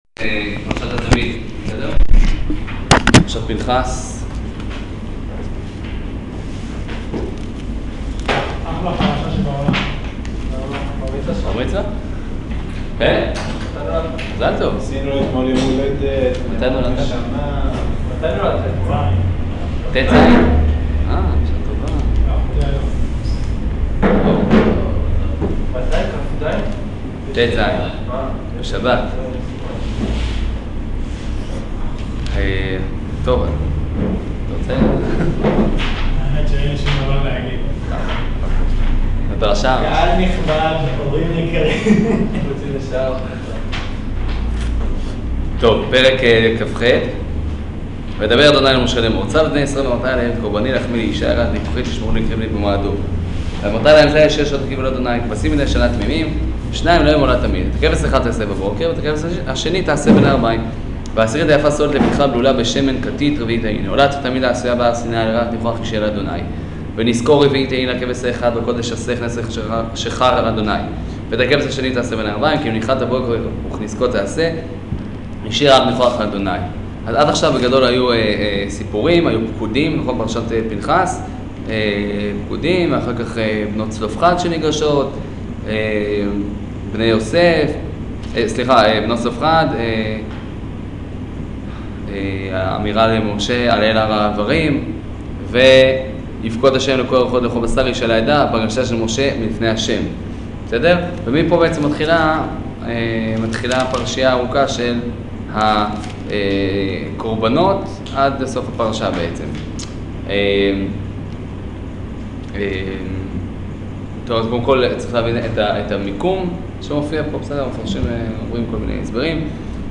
שיעור פרשת פנחס